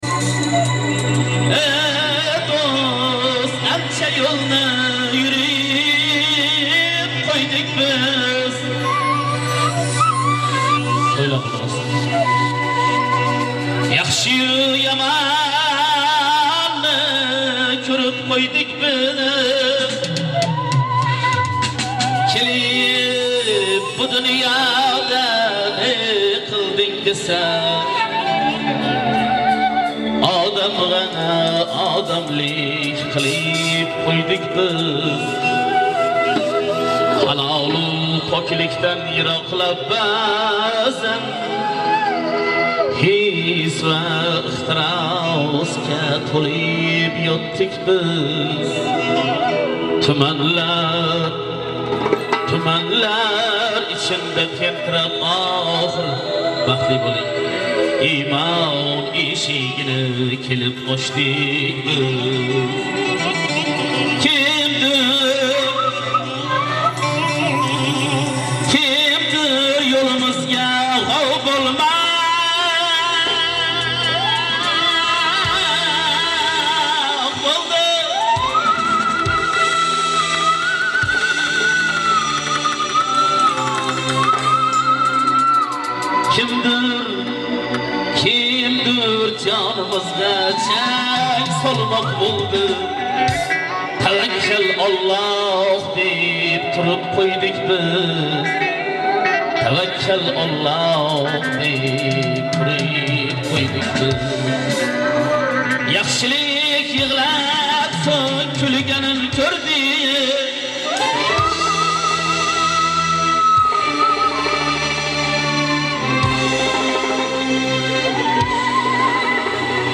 Jonli Ijro